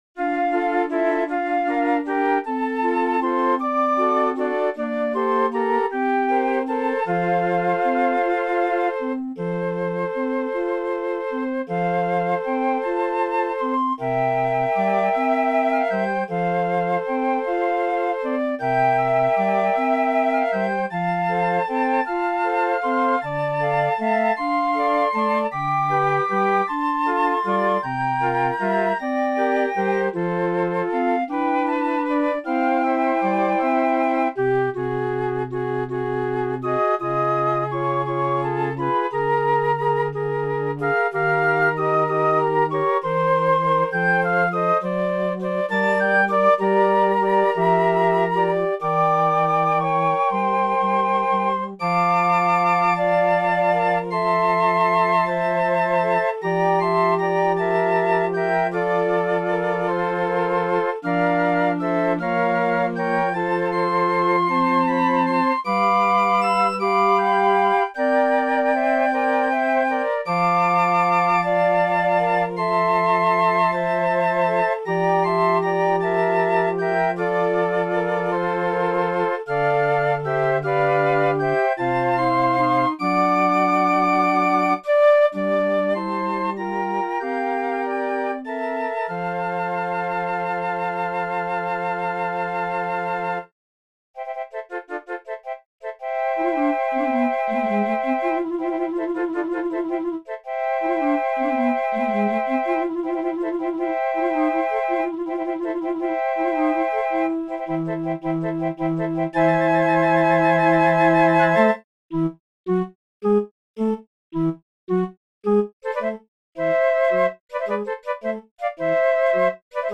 medley for six flutes (psssab)